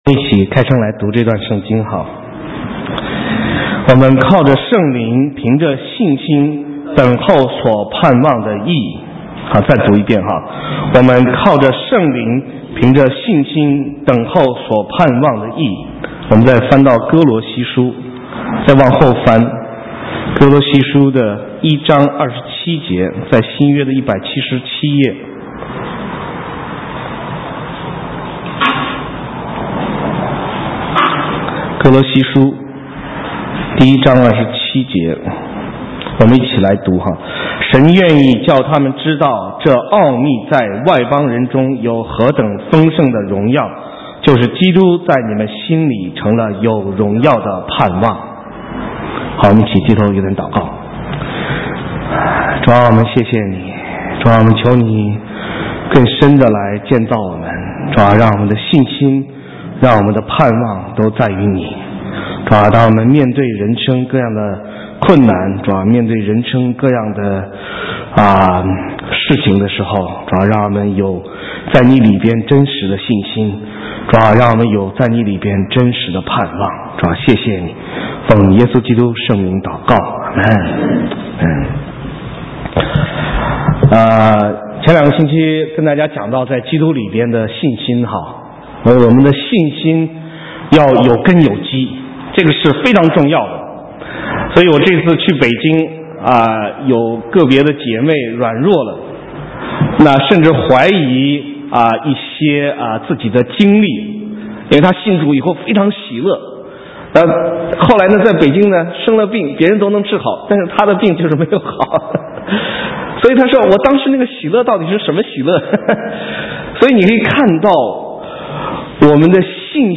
神州宣教--讲道录音 浏览：在基督里荣耀的盼望 (2010-09-26)